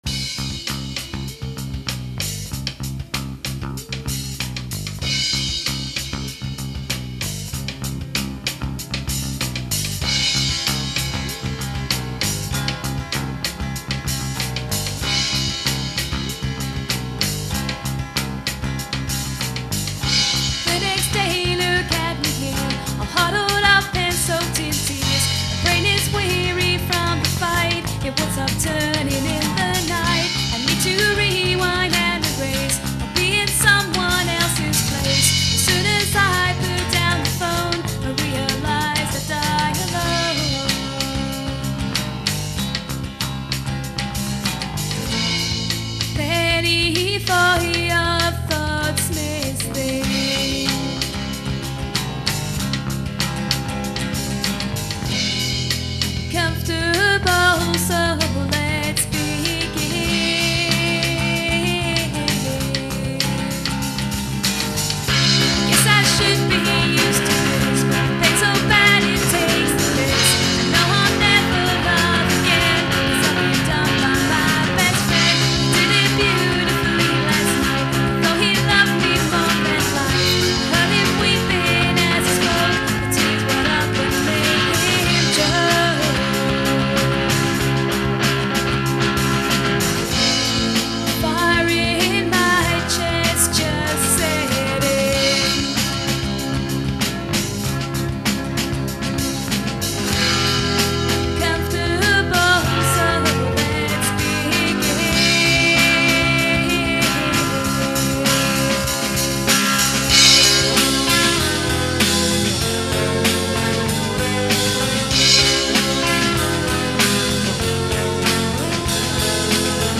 Demo Recordings